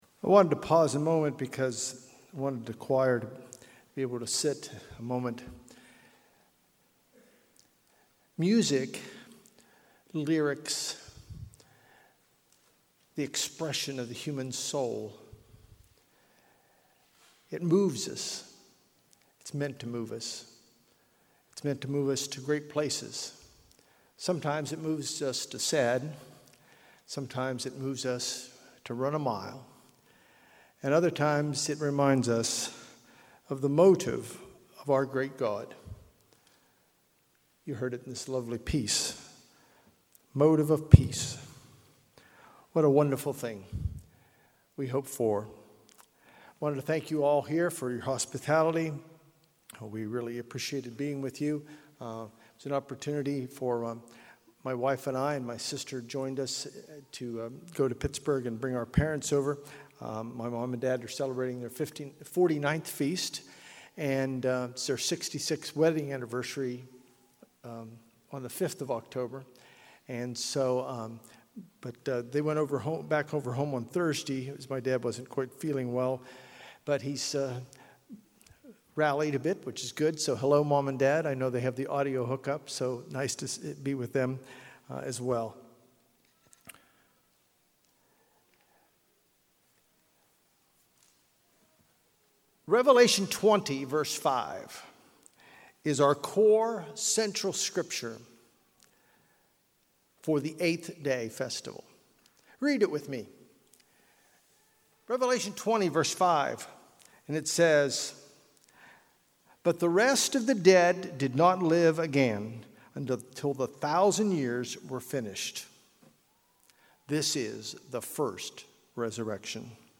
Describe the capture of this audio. This sermon was given at the Ocean City, Maryland 2022 Feast site.